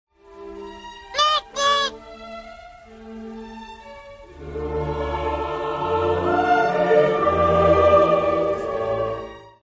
Noot Noot! - Pingu Sound Effect Free Download